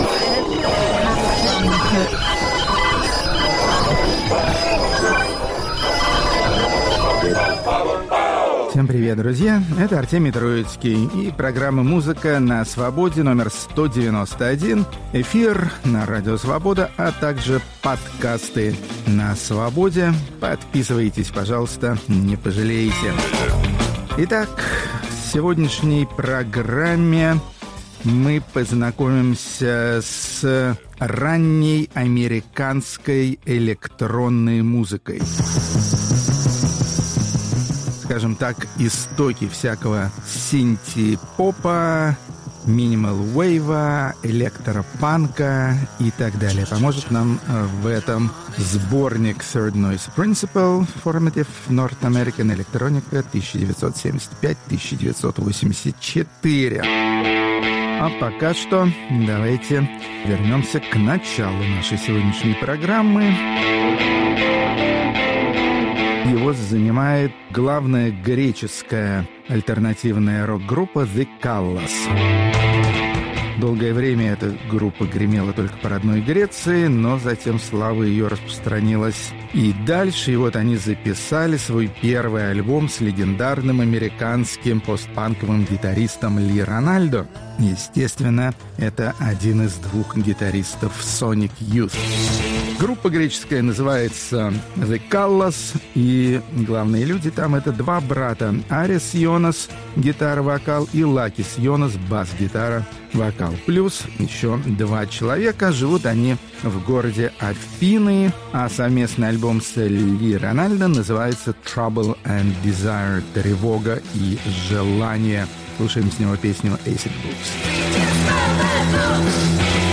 Исполнители "новой электронной волны" из Северной Америки, творившие в 1970–1980-е годы и с той поры незаслуженно забытые. Рок-критик Артемий Троицкий воспевает подвиги сбитых лётчиков и представляет лучшую полудюжину композиций из целых шести десятков.